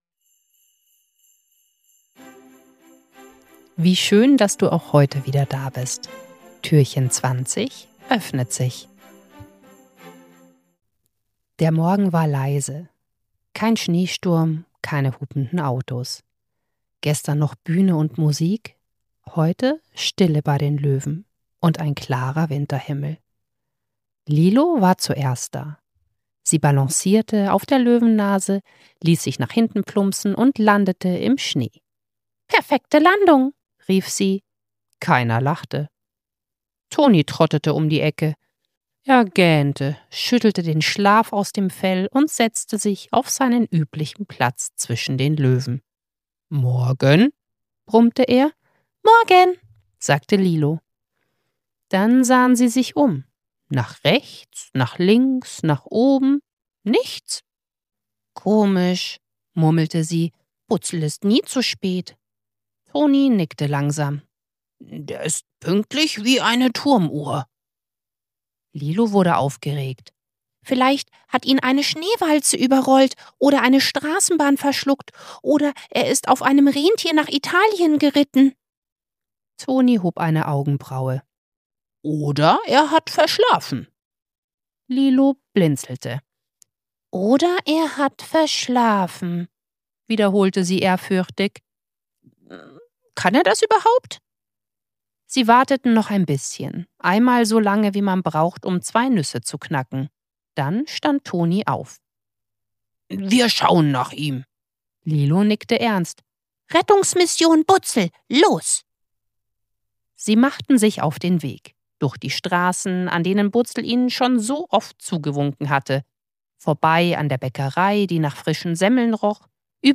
20. Türchen – Butzel und die Mehlwolke ~ Butzels Adventskalender – 24 Hörgeschichten voller Herz & kleiner Wunder Podcast